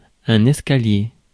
Ääntäminen
IPA : [.ˌweɪ]